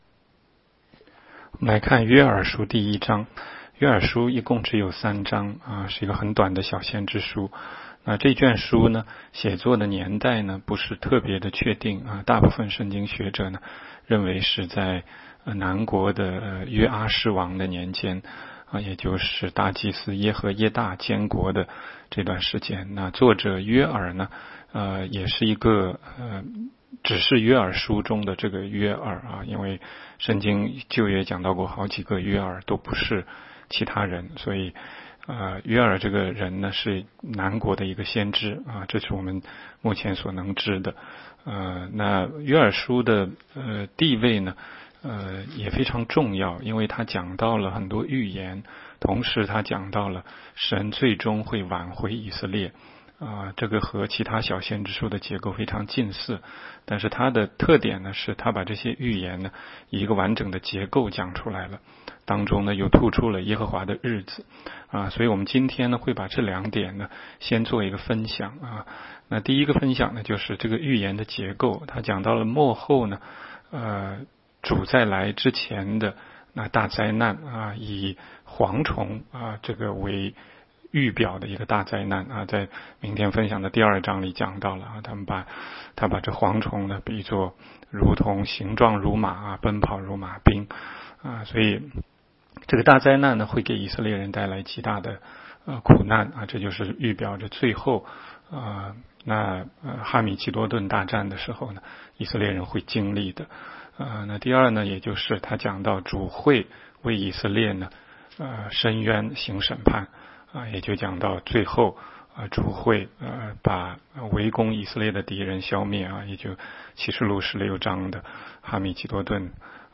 16街讲道录音 - 每日读经 -《约珥书》1章